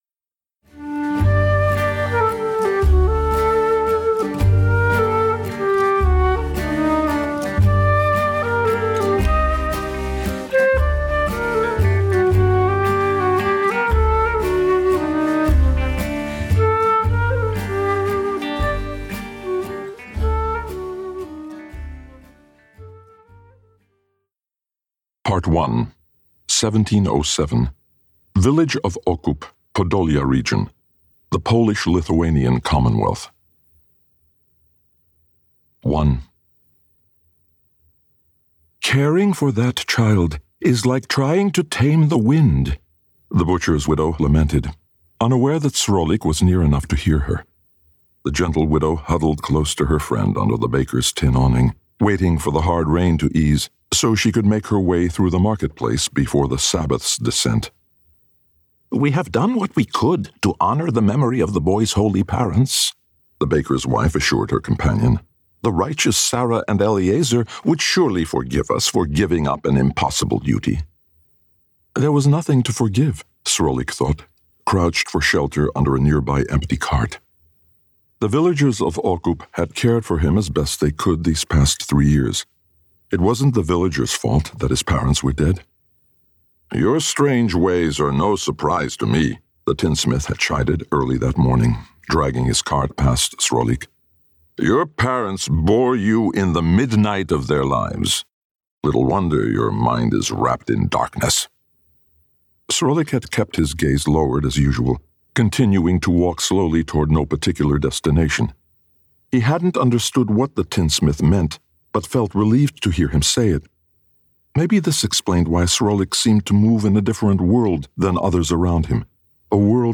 Listen to the opening of Chapter 1 of the forthcoming audiobook edition of The Tremble of Love: A Novel of the Baal Shem Tov.
Music by Klezamir.
His voice seems to convey the beauty of the words.